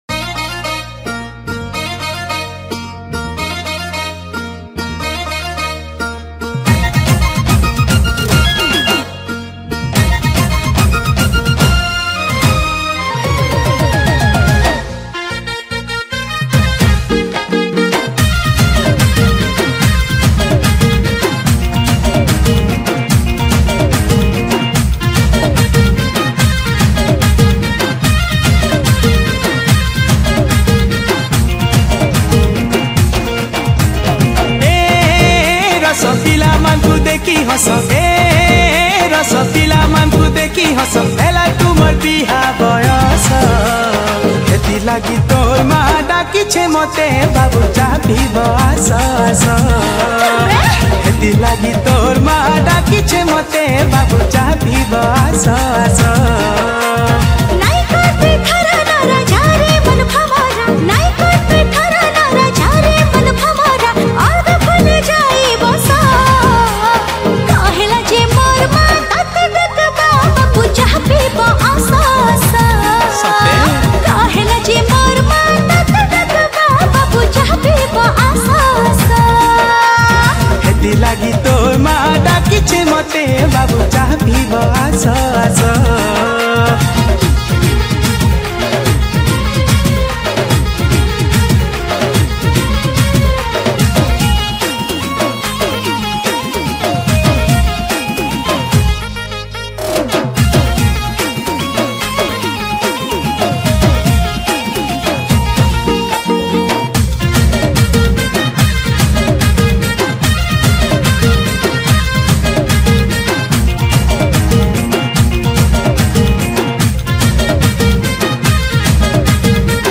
Track recorded at RR Studio cuttack